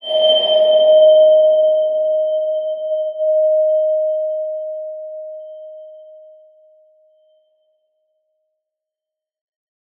X_BasicBells-D#3-mf.wav